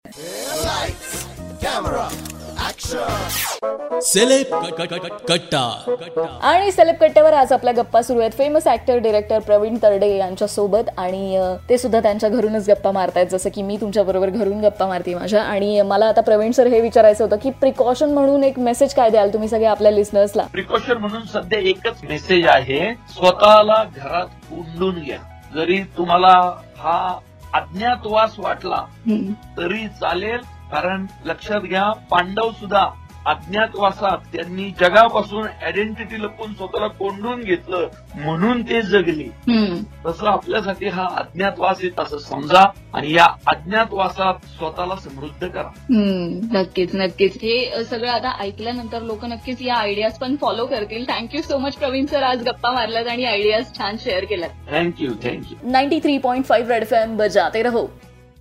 In this interview Pravin Tarde gaves some Precautionary tips for listeners..